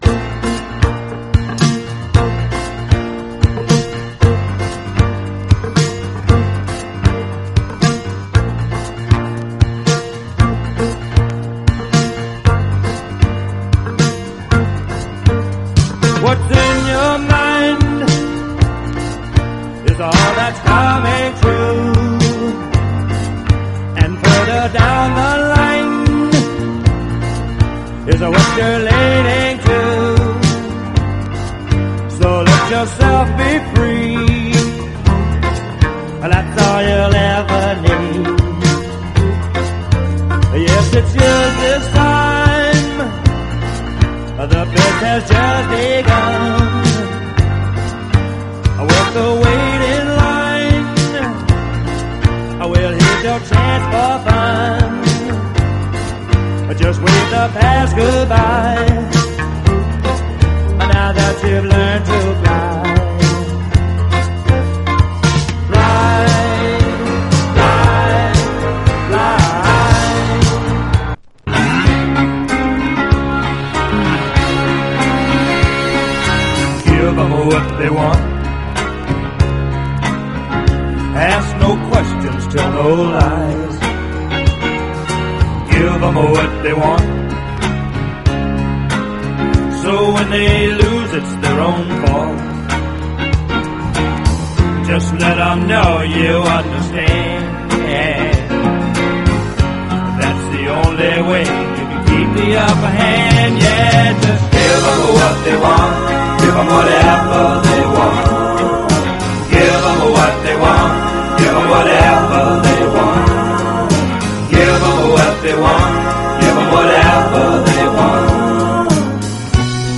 SOUL / SOUL / 70'S～ / PHILADELPHIA SOUL / PHILLY SOUL